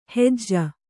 ♪ hejj